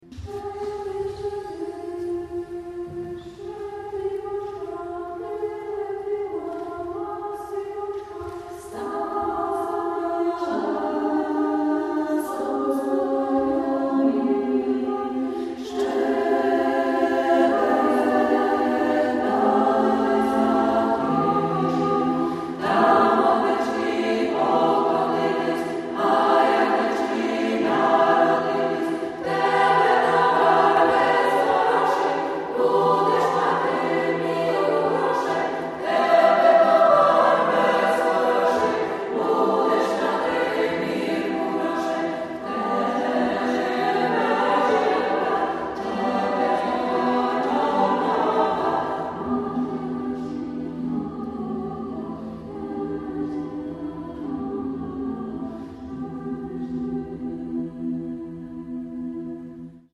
Sotiria - Szczedrik (muz. ukraińska, oprac. Mykola Leontowicz)
Niniejszy utwór jest chroniony prawem autorskim i został udostępniony przez chór Sotiria na prawach wyłączności na użytek Multimedialnej Encyklopedii Gorzowa Wielkopolskiego.